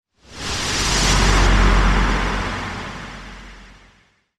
MAGIC SPELL Fade In Wobble Noise Fade Out (stereo).wav